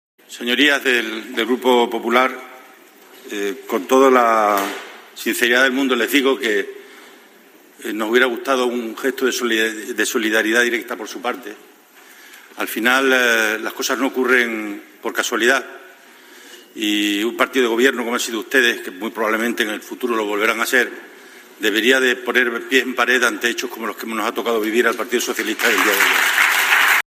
Fernández Vara apelaba a la solidaridad por parte del Partido Popular en una intervención en el Senado, donde ocupa un escaño
Guillermo Fernández Vara en el Senado